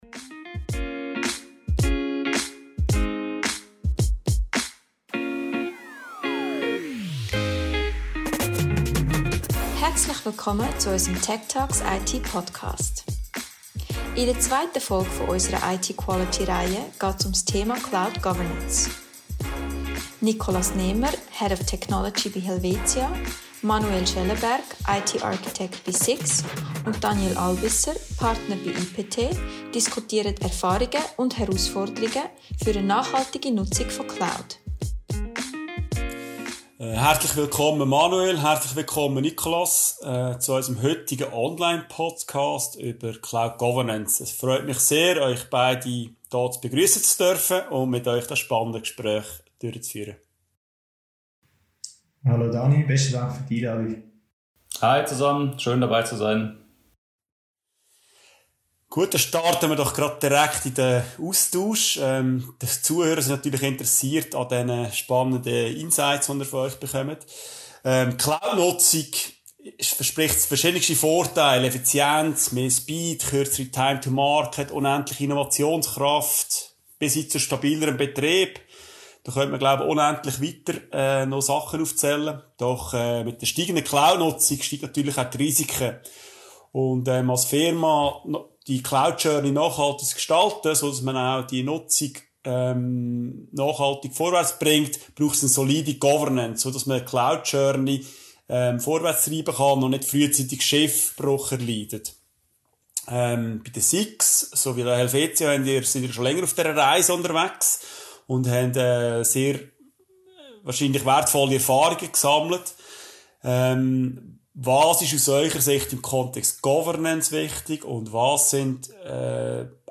Beschreibung vor 5 Jahren In der zweiten Folge unserer IT Quality Reihe geht es um den Aufbau von Cloud Governance. Zwei Experten aus der Versicherungs- und Finanzbranche diskutieren ihre Erfahrungen und geben den Zuhörerinnen und Zuhörern Tipps zur Umsetzung.